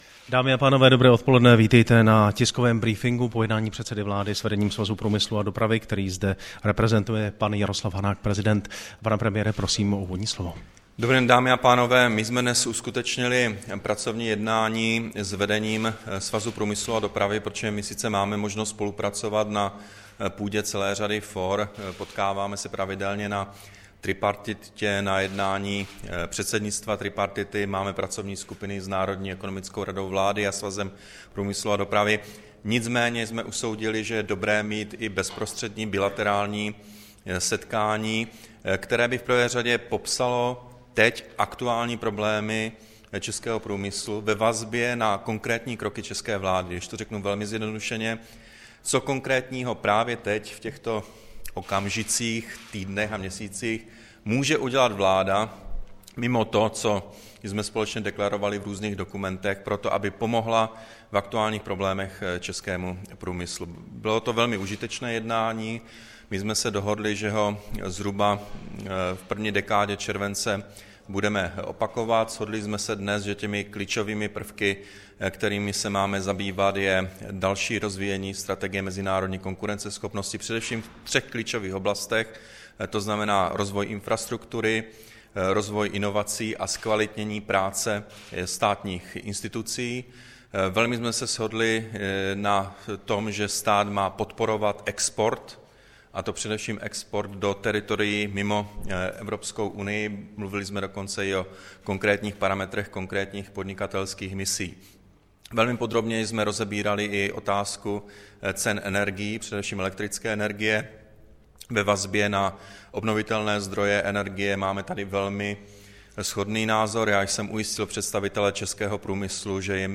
Tiskový brífink po jednání premiéra se zástupci Svazu průmyslu a dopravy, 2. května 2013